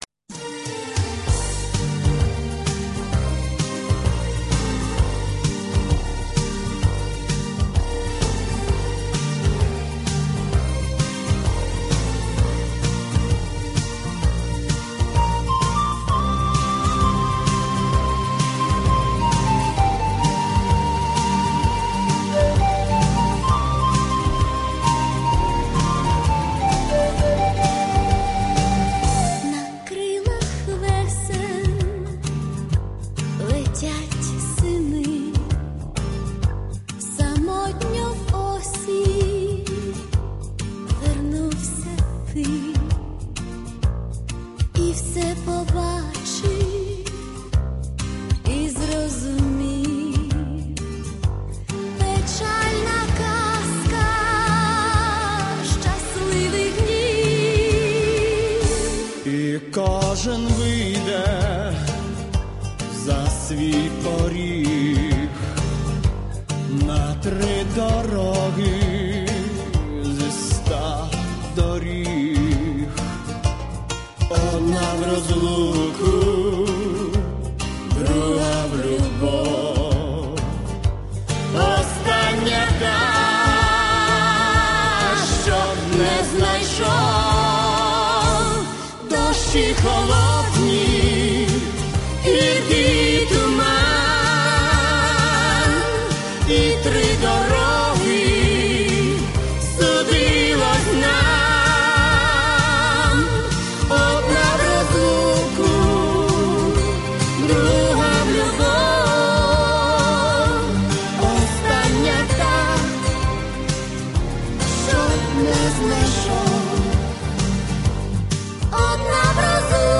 Плюсовий запис